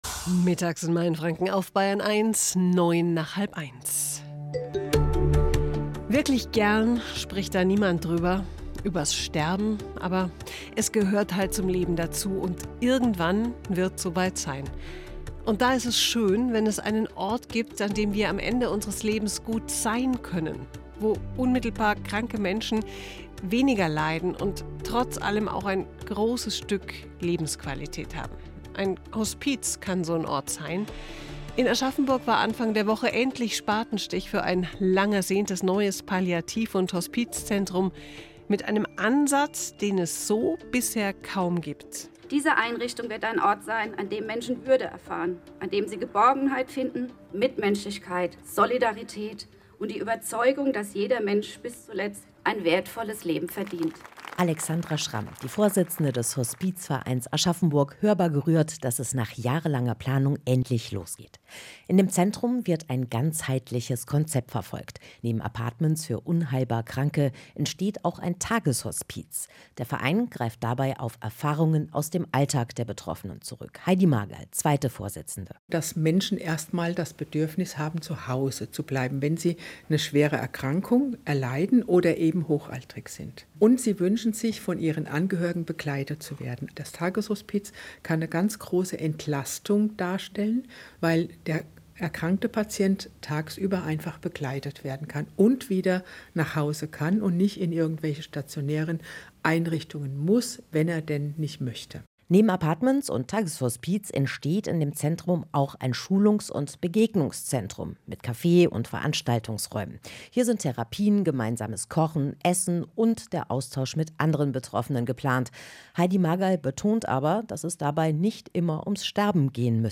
Hier ein Mitschnitt des Radiobeitrages in Bayern1.
Mittschnitt-Bayern-1-Hospiz-Aschaffenburg.mp3